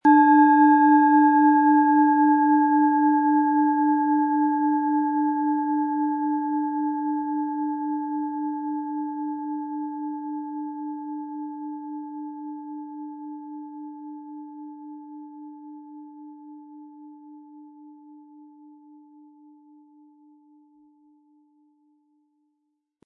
Planetenschale® Verlässlich sein & Grenzen setzen mit Saturn, Ø 12,5 cm, 320-400 Gramm inkl. Klöppel
Planetenton 1
Nach uralter Tradition von Hand getriebene Planetenklangschale Saturn.
MaterialBronze